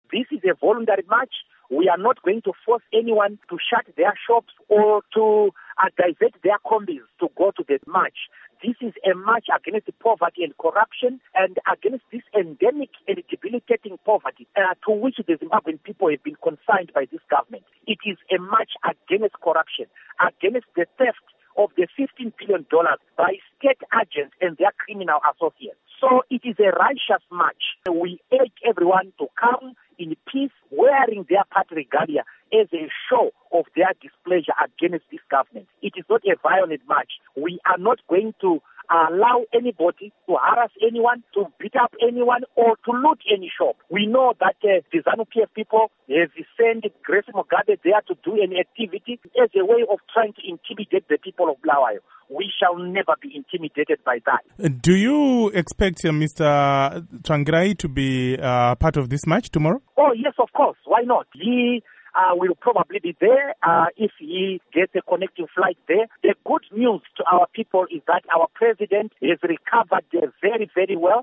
Interview With Douglas Mwonzora MDC-T Organising Secretary